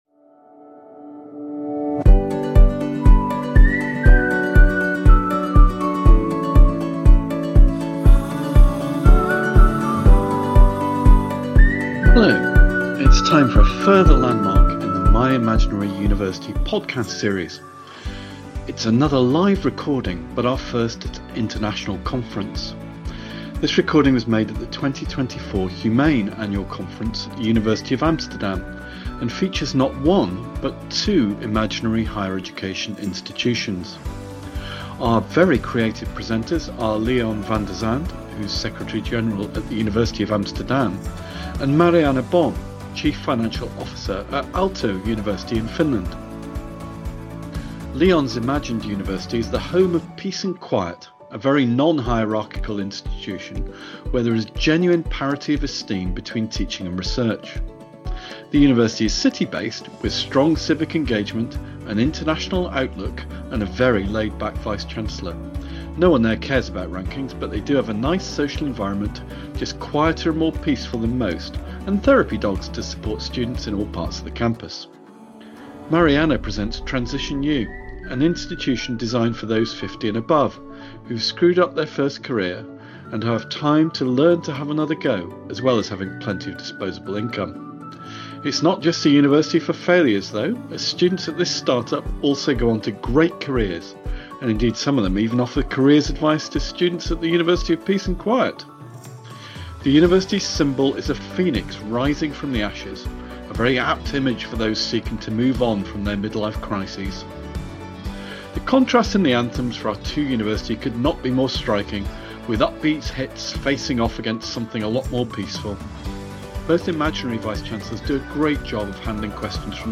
Time for a further landmark in the My Imaginary University podcast series. It is another live recording but our first at an international conference.